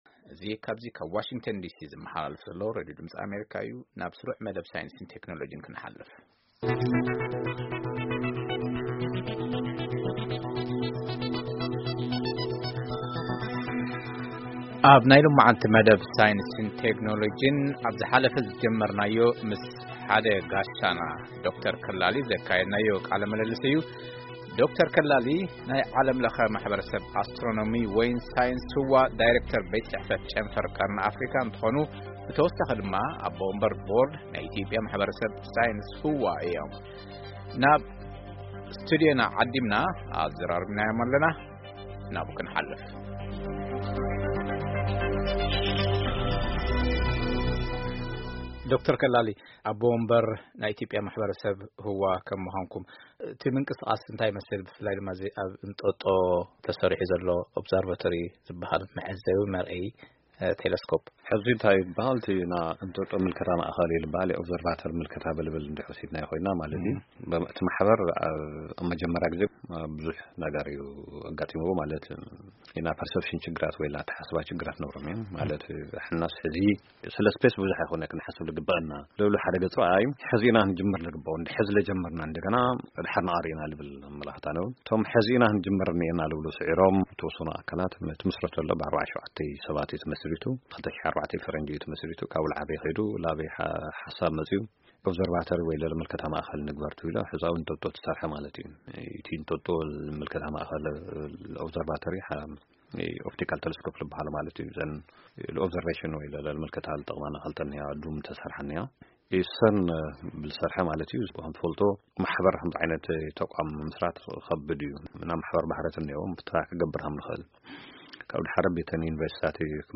ቃለ ምልልስ